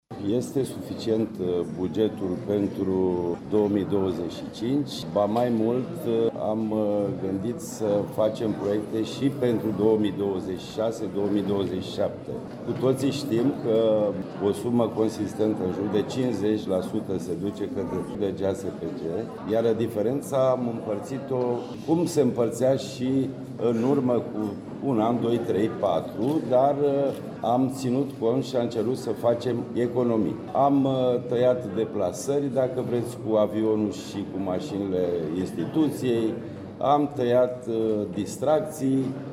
Președintele Consiliului Județean Constanța, Florin Mitroi, spune că au fost făcute economii pentru a se putea face investiții.